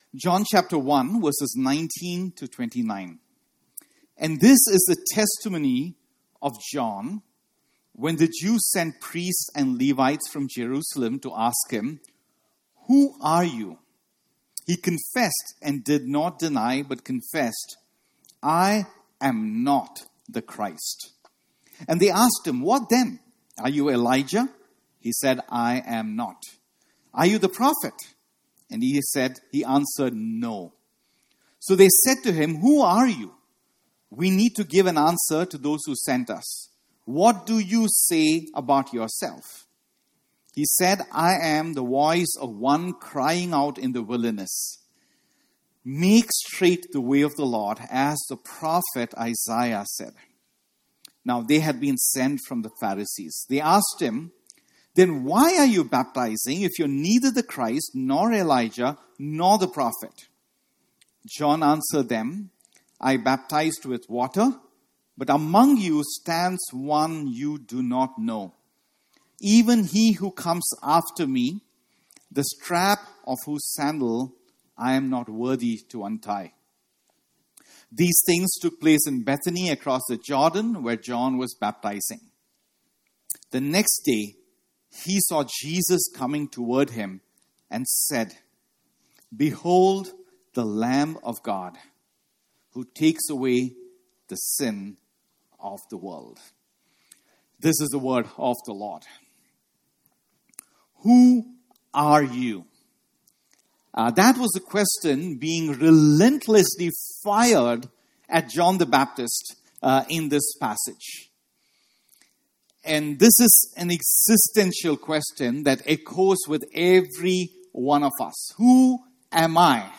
… continue reading 200 episodes # Religion # Sermons Messages # New City Church # Christianity